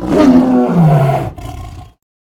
CosmicRageSounds / ogg / general / combat / creatures / tiger / he / die1.ogg